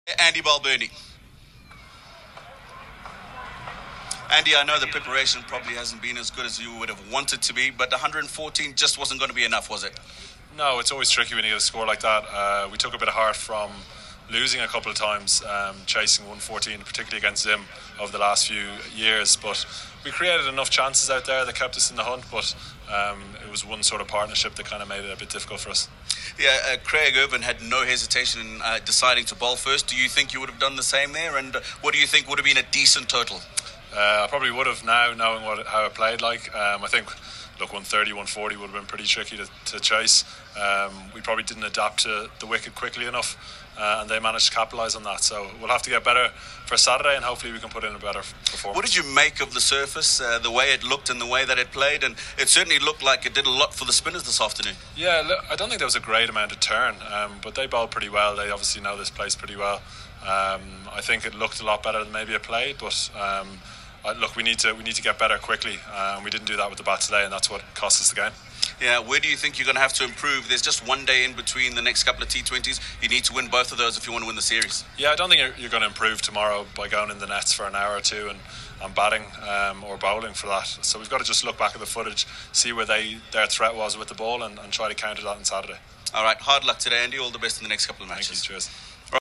Andrew Balbirnie speaks following Ireland loss to Zimbabwe